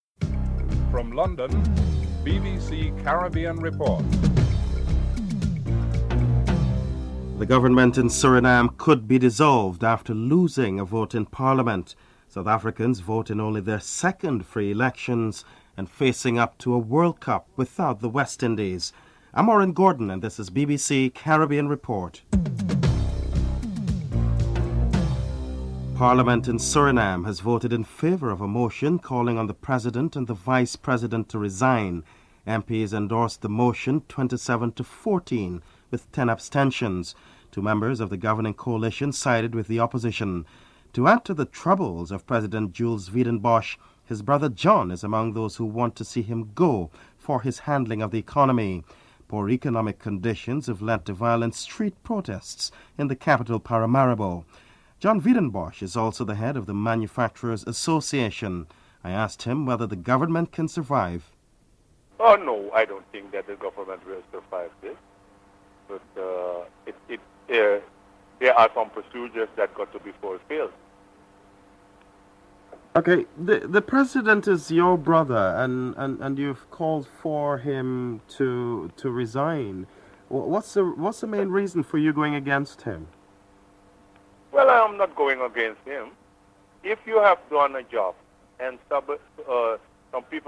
Vivian Richards discusses his role as coach of the West Indies cricket team (09:42 - 15:27)